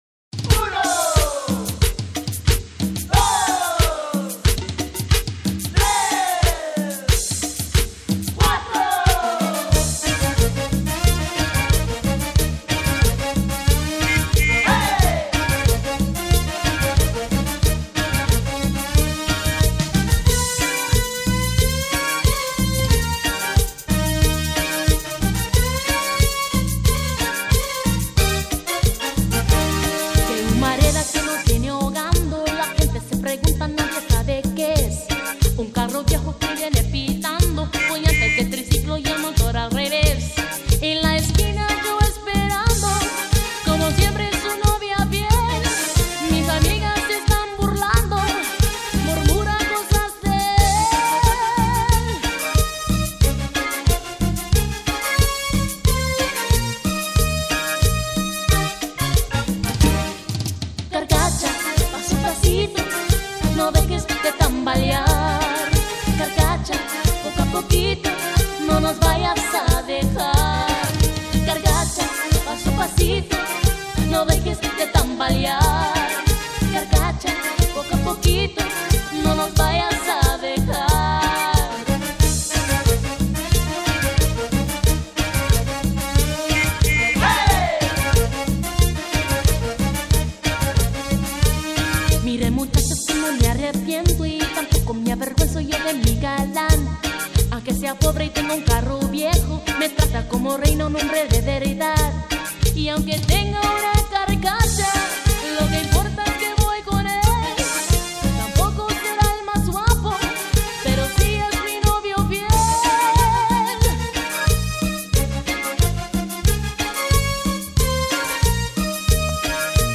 texmex